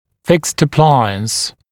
[fɪkst ə’plaɪəns][фикст э’плайэнс]несъемный аппарат, несъемная аппаратура